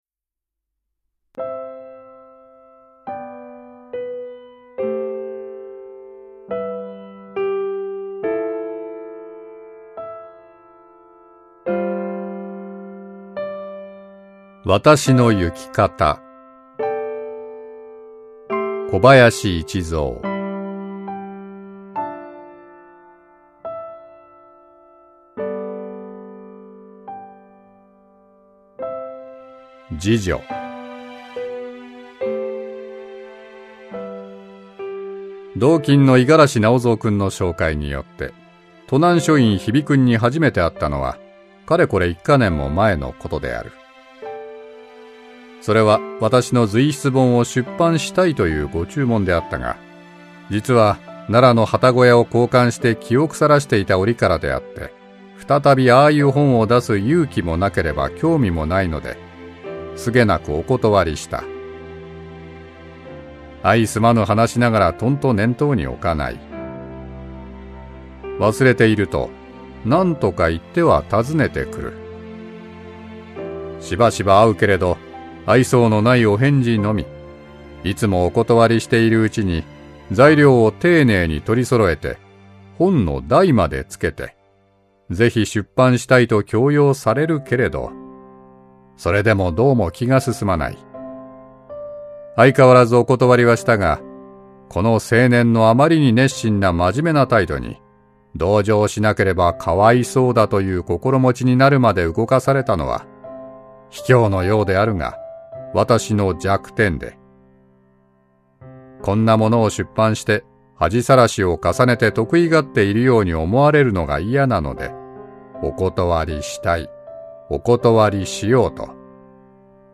[オーディオブック] 私の行き方